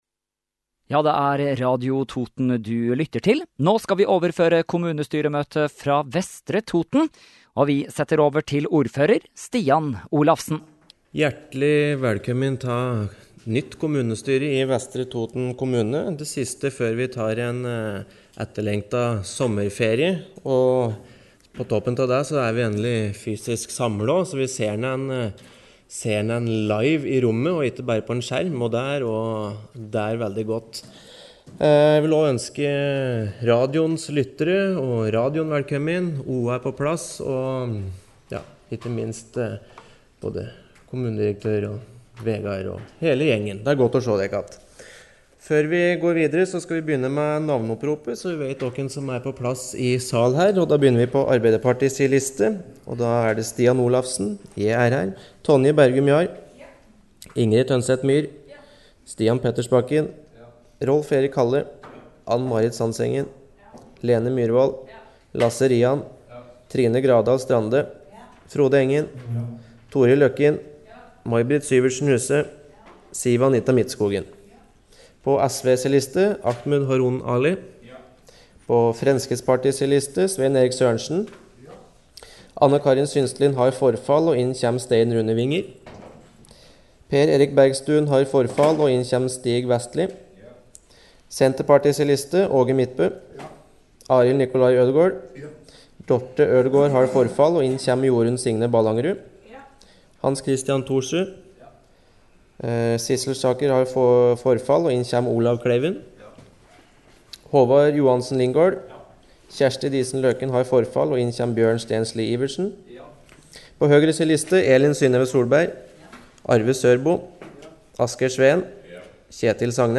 Kommunestyremøte fra Vestre Toten 25. juni 2020 kl. 09:00 - 11:15